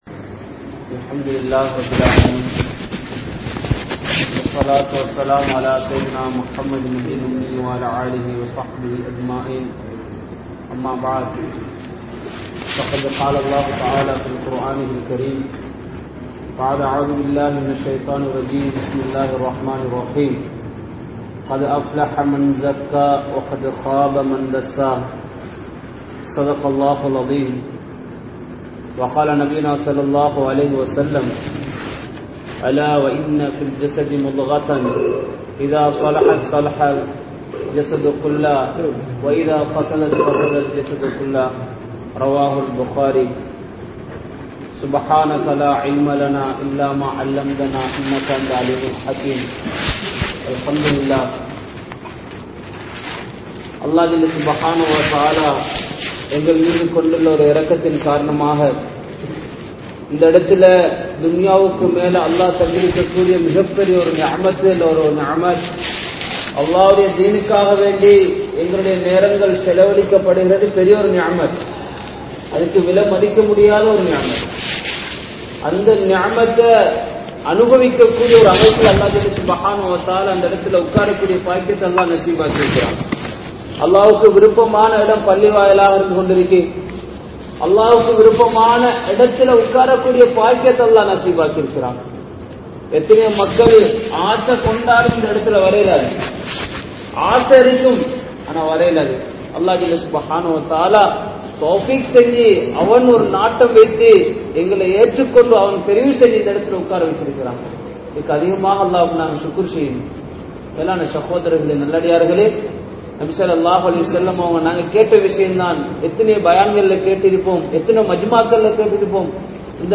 Paavaththil Inpam Adainthavarhal (பாவத்தில் இன்பம் அடைந்தவர்கள்) | Audio Bayans | All Ceylon Muslim Youth Community | Addalaichenai